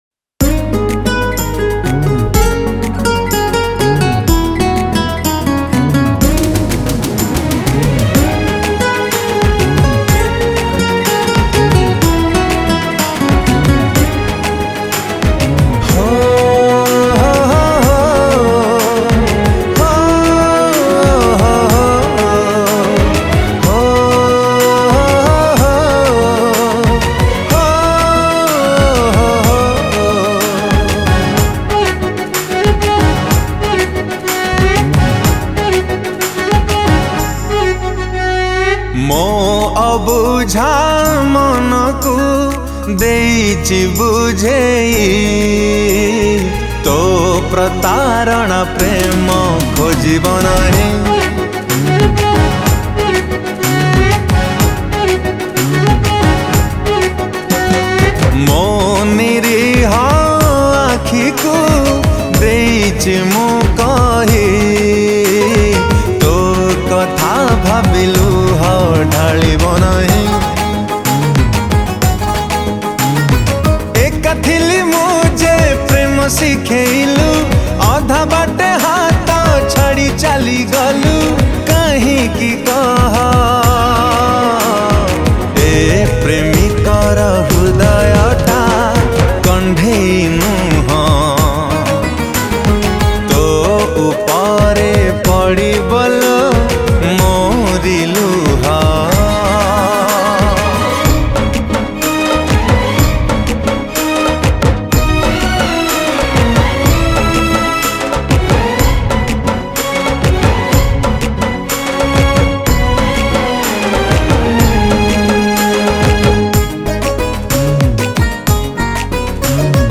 Song Type :Sad